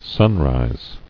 [sun·rise]